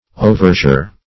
Oversure \O"ver*sure"\, a. Excessively sure.